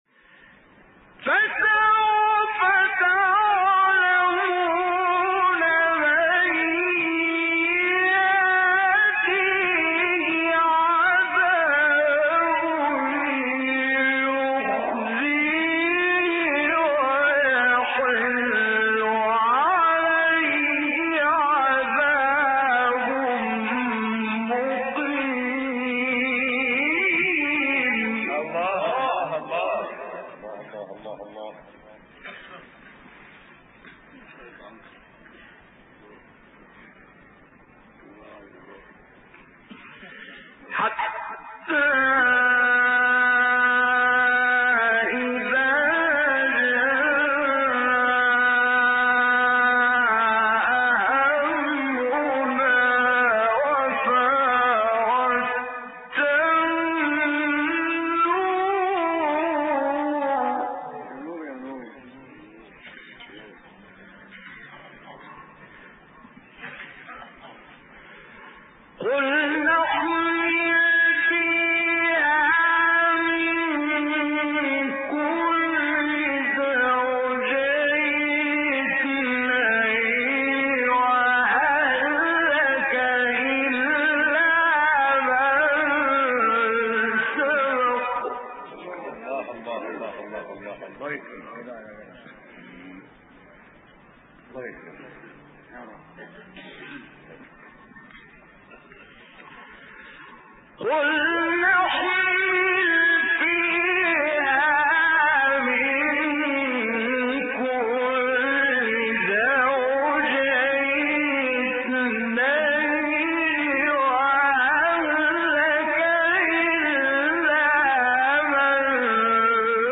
سوره : هود آیه : 39-40 استاد : حمدی زامل مقام : مرکب خوانی ( رست * بیات) قبلی بعدی